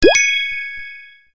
开始音效.mp3